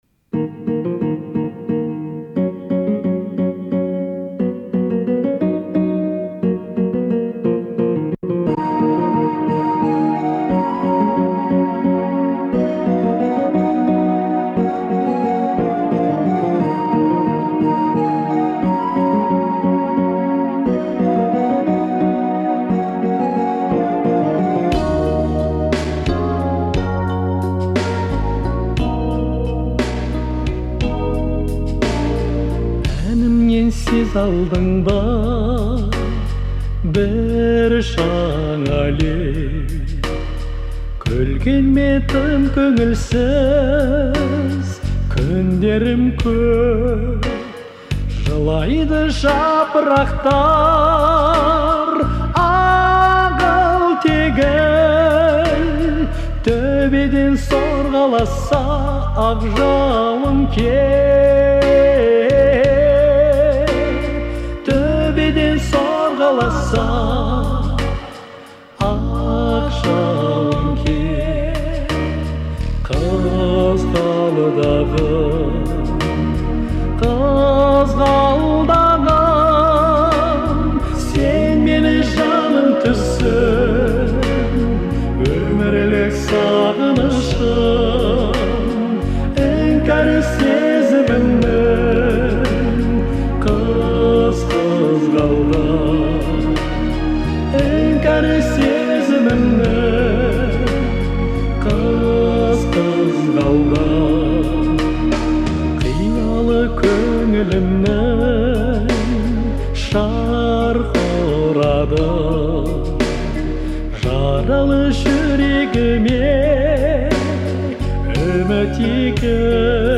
это популярная казахская песня в жанре народной музыки